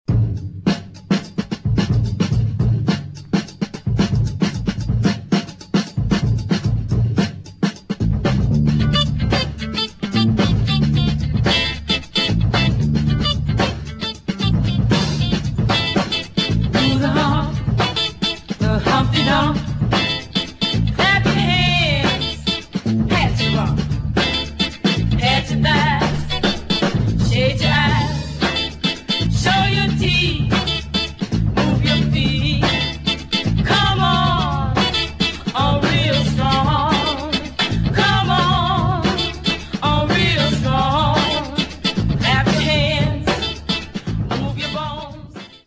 [ JAZZ / FUNK / DOWNBEAT ]